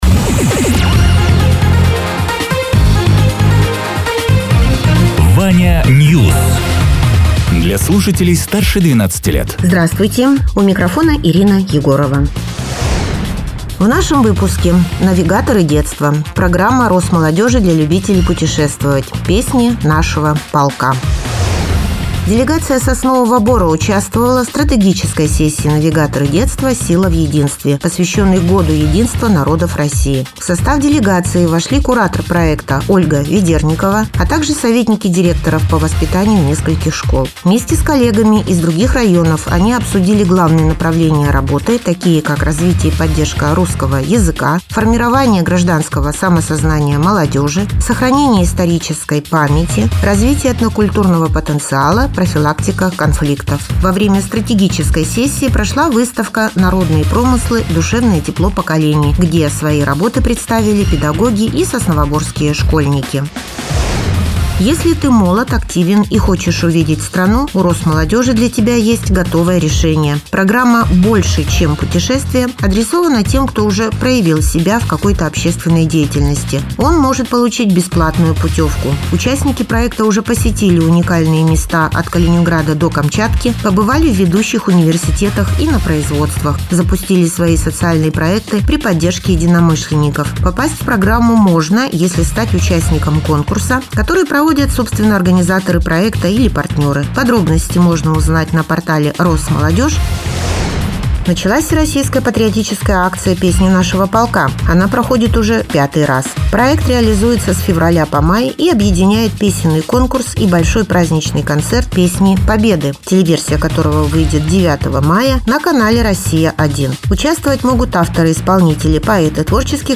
Радио ТЕРА 29.03.2026_10.00_Новости_Соснового_Бора